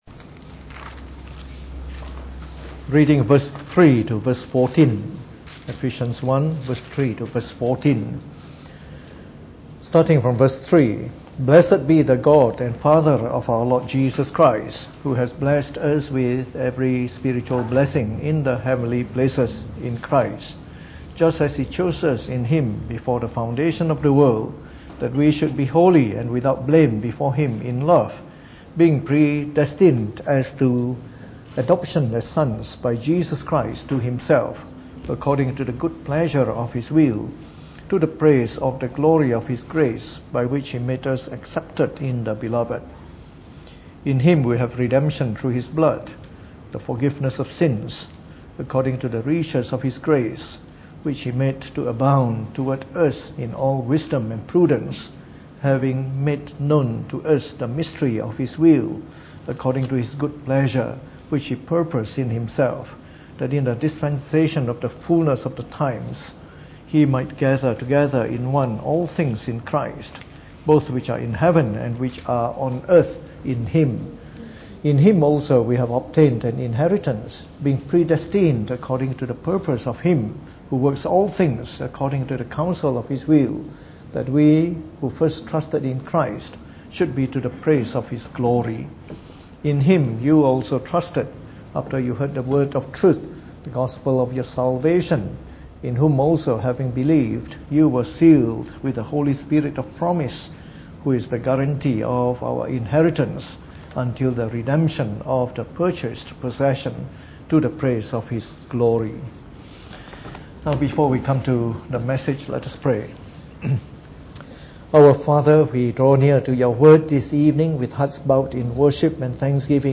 Preached on the 14th of November 2018 during the Bible Study, from our series on Missions.